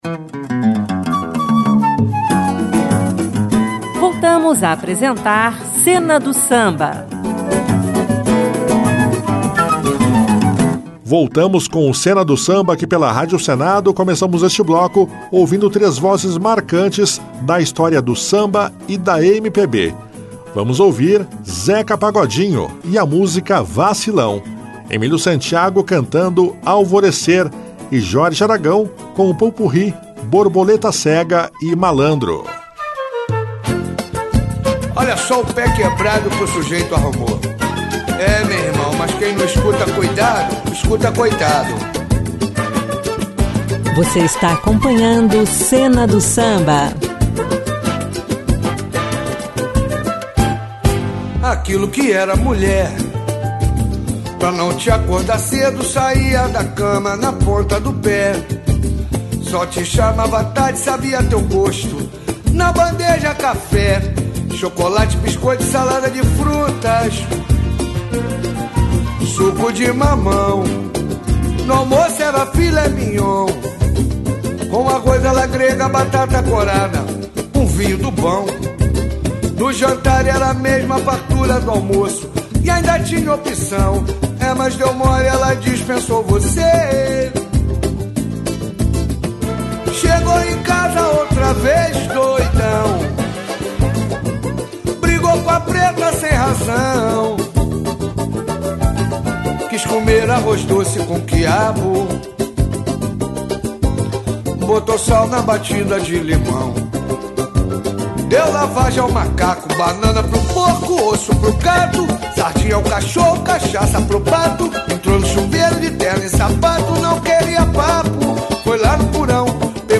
uma seleção musical repleta de sucessos